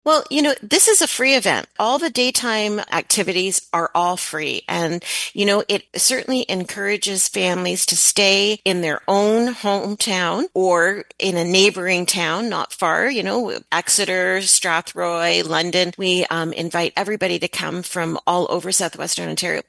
Now in its fourth year, the local festival has grown from a small pandemic-era celebration into a marquee summer event notes the Mayor, featuring family-friendly fun, free public swimming and skating, local food, live music and more.